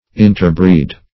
Interbreed \In`ter*breed"\, v. t. & i.